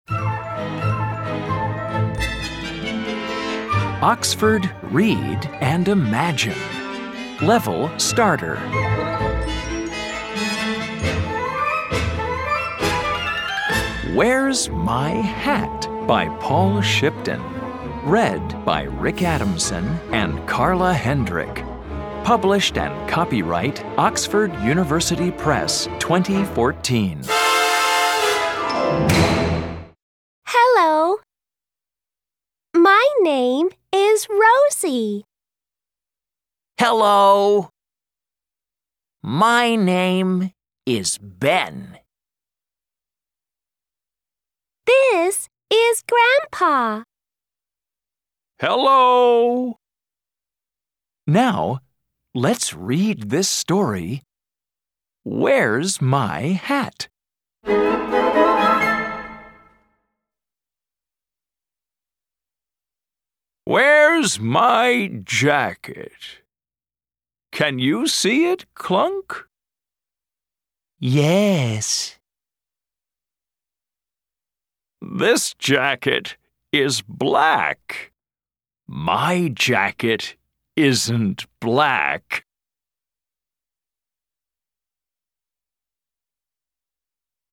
Track 1 Where's My Hat US English.mp3